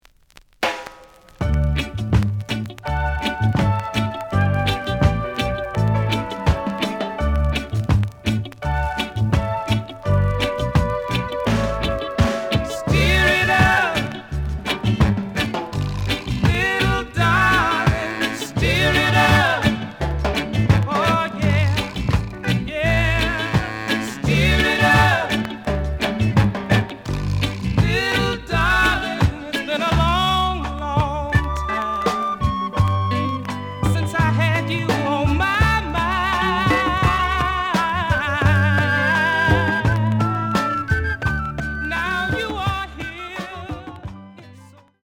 The audio sample is recorded from the actual item.
●Format: 7 inch
●Genre: Reggae